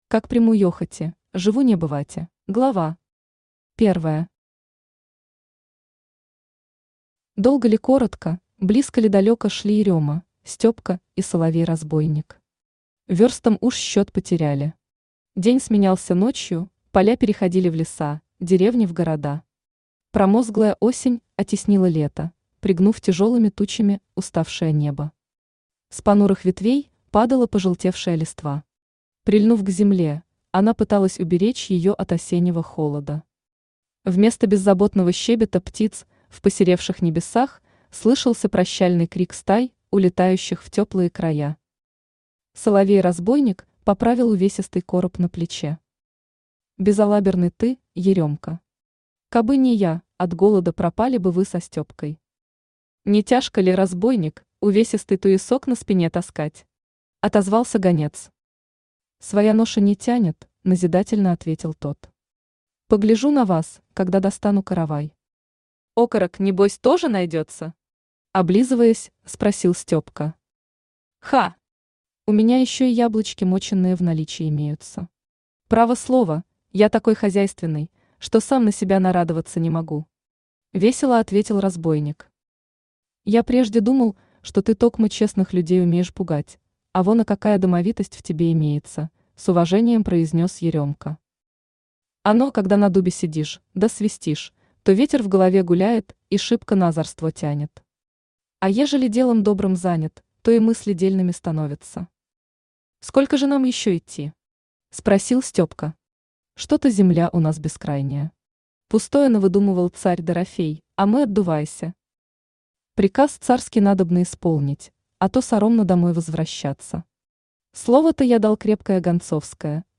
Аудиокнига Край Земли. Книга вторая | Библиотека аудиокниг
Книга вторая Автор Ольга Владимировна Манько Читает аудиокнигу Авточтец ЛитРес.